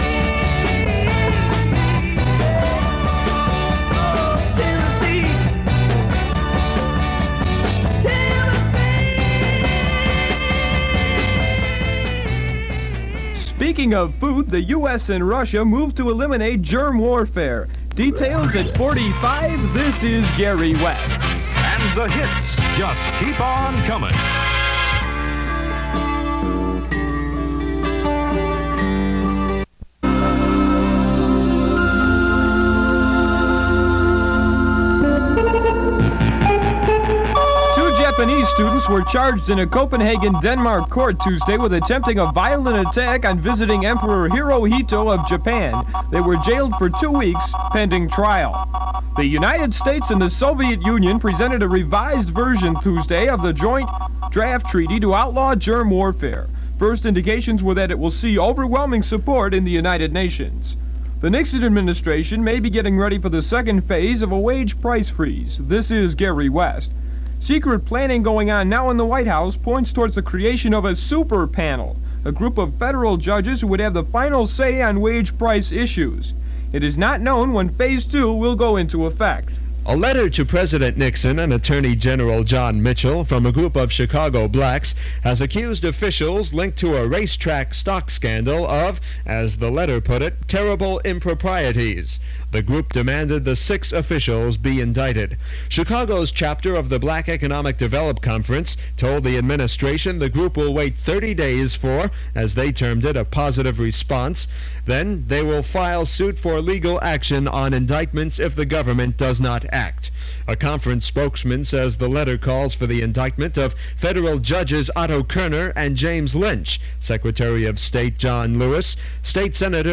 We had to use newspapers, SIU press releases and a few recorded reports, mostly outdated.